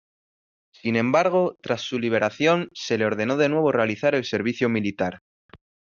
li‧be‧ra‧ción
/libeɾaˈθjon/